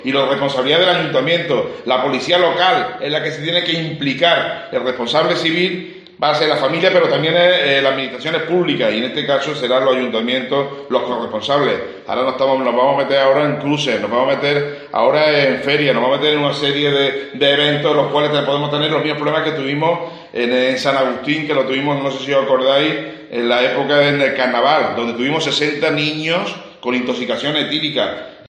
Escucha al senador popular Jesús Aguirre